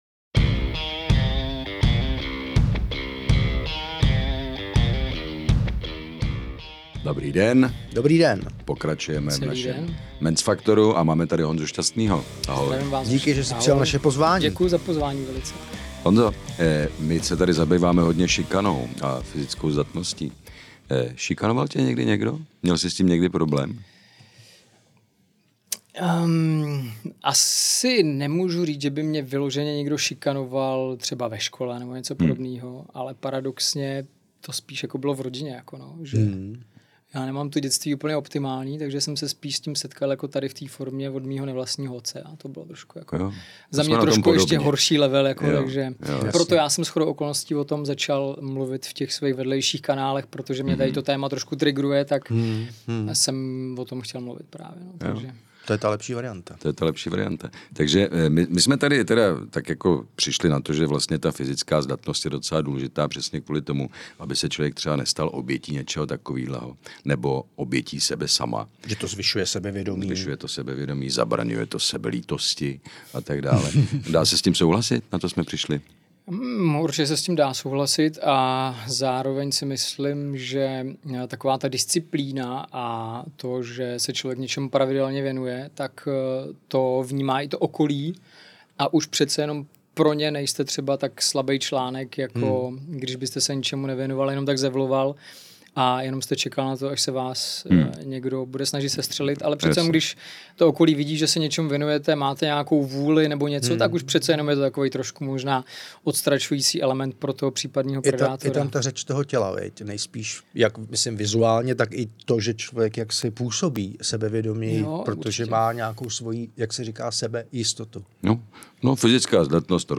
Velkou část rozhovoru věnoval i kritice fitness influencerů.